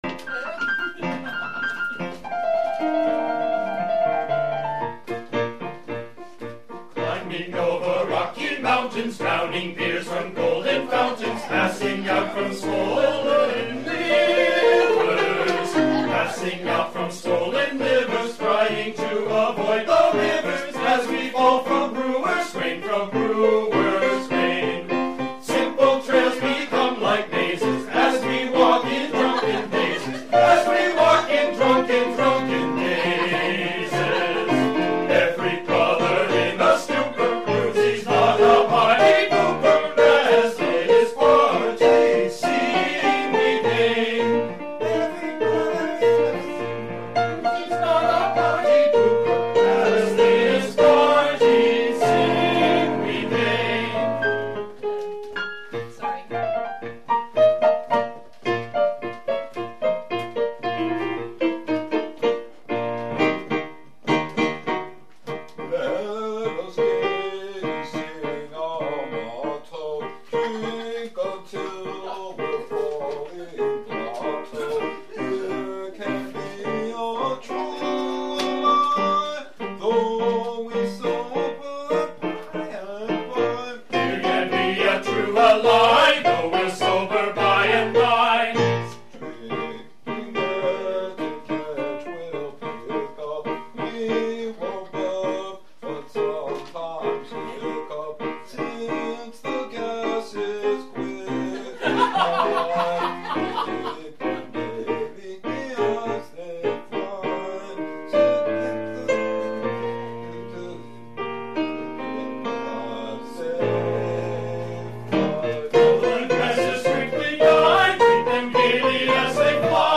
CHORUS - Climbing Over Rocky Mountains